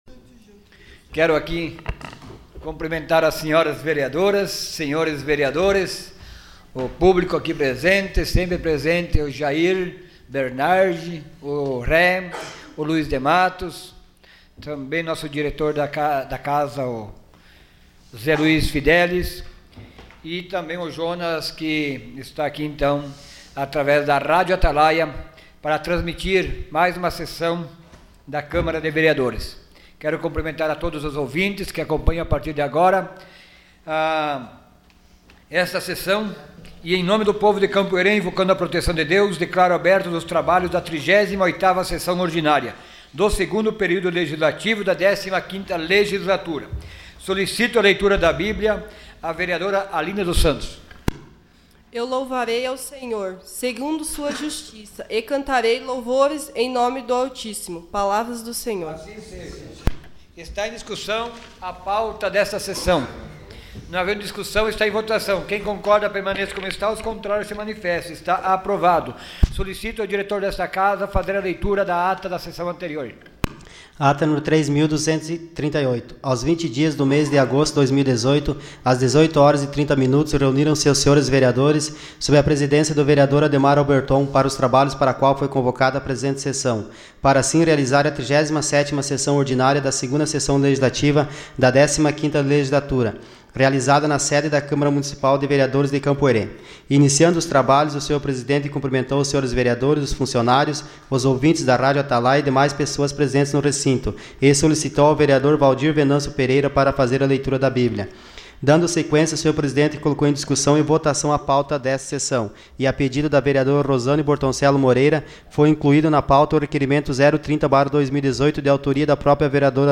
Sessão Ordinária dia 23 de agosto de 2018.